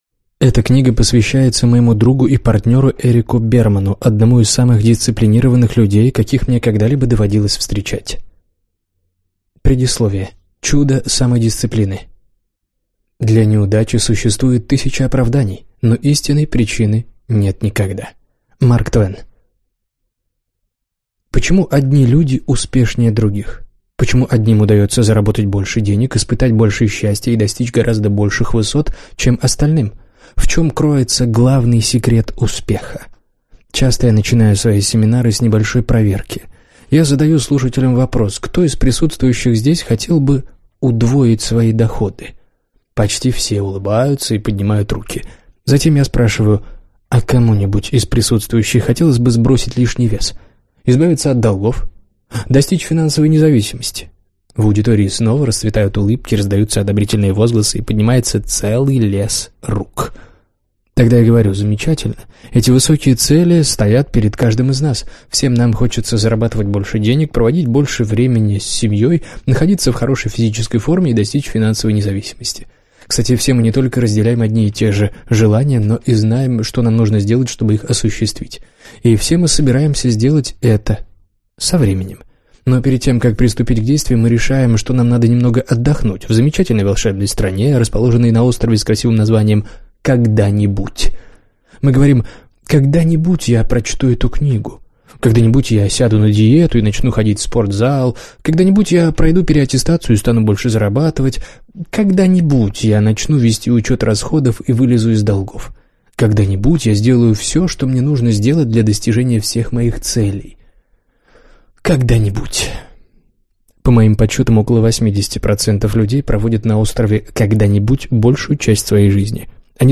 Аудиокнига Нет оправданий! Сила самодисциплины | Библиотека аудиокниг